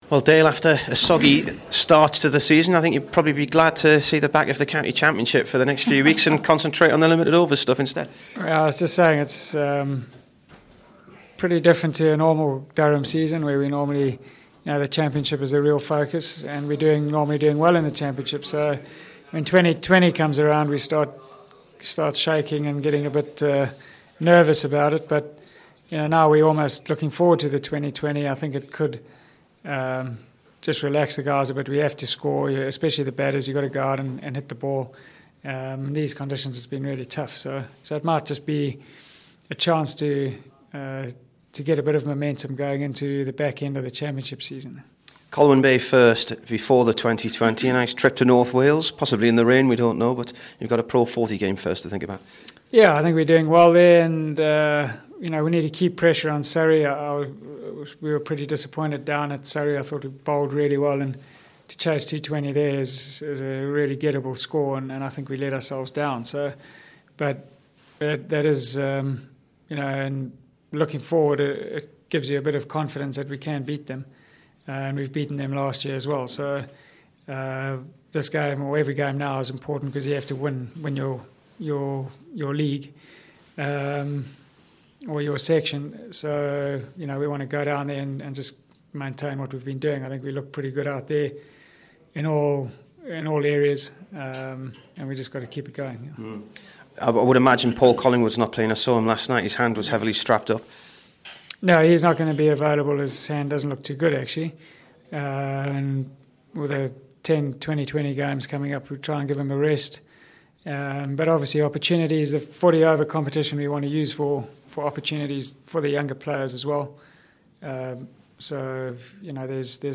Dale Benkenstein int